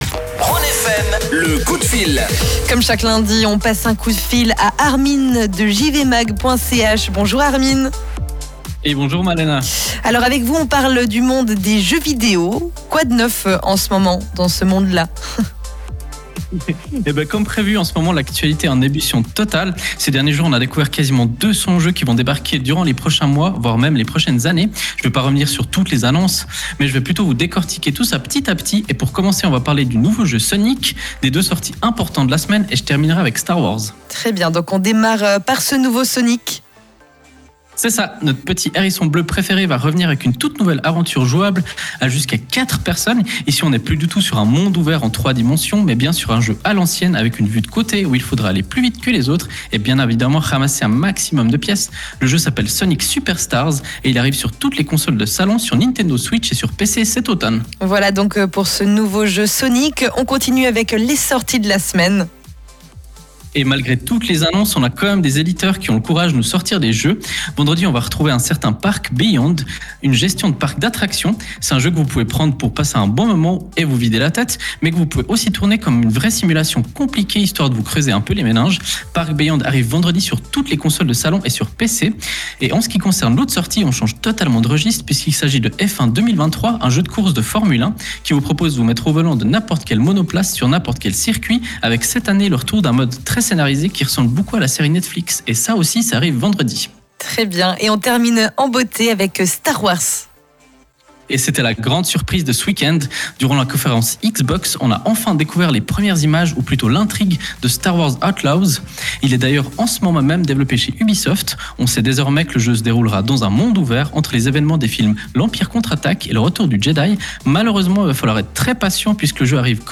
Le direct est à réécouter juste en dessus, comme d’habitude.